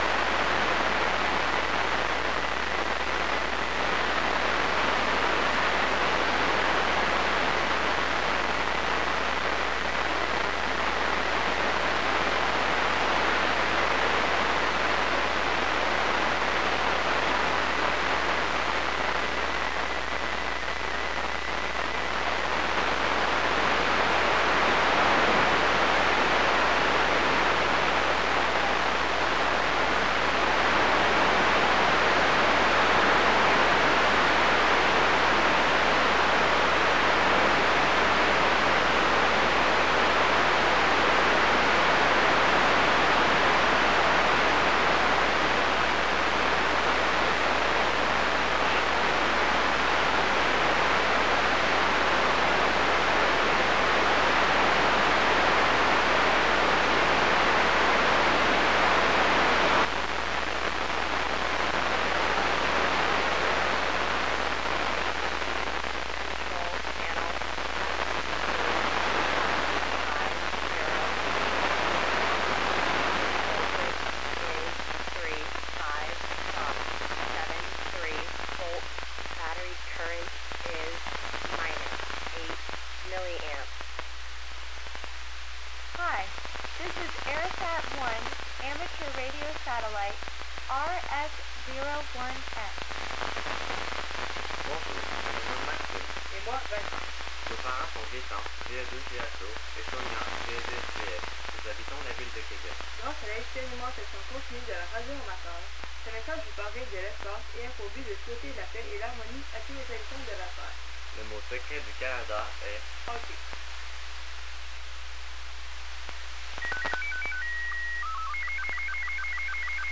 Arissat1 on a jpole 20 feet up ( aug 14 2011) with SSTV
here is the entire recording of tonights arissat1 pass including the sstv
voice message and sstv trnasmission
arisssatsstv.mp3